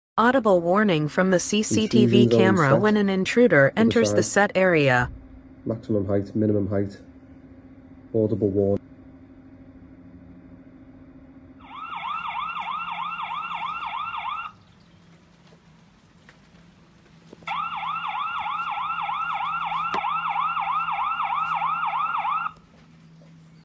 Built in alarms automatically activating sound effects free download
Built in alarms automatically activating when an intruder enters the set area.